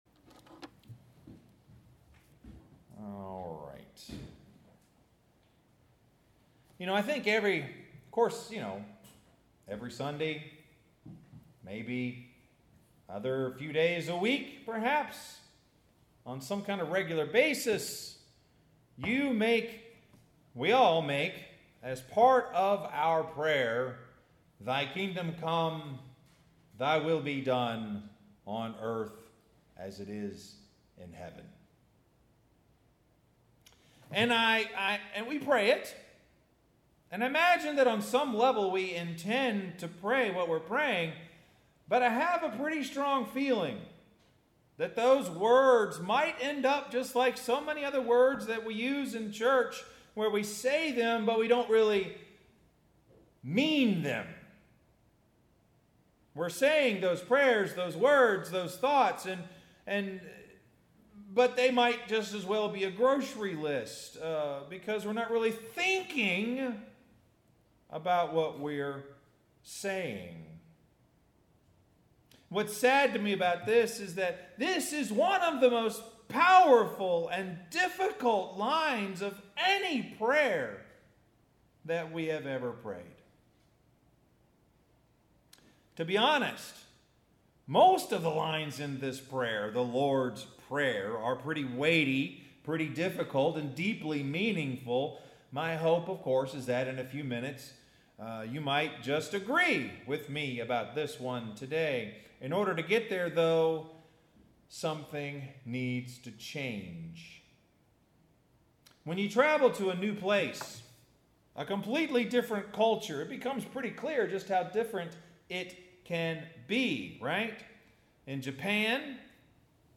[Sorry, I did not include the Scripture reading or the special music!]